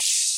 open hat 2.wav